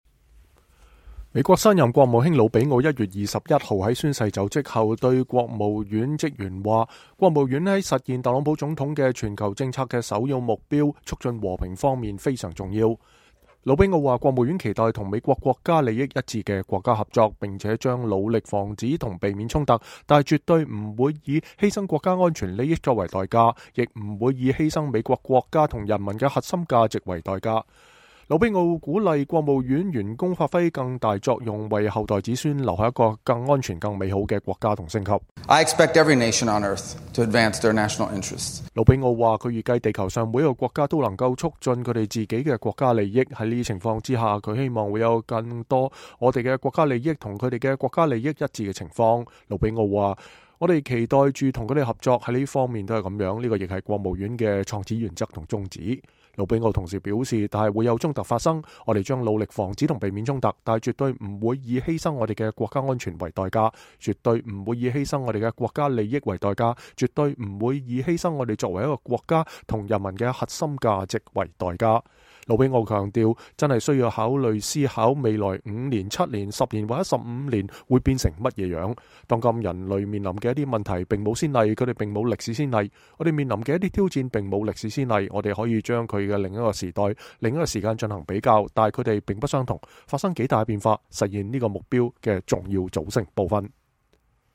新人美國國務卿魯比奧在1月21日上任首日在國務院總部内向各部門職員發表演説。（路透社照片）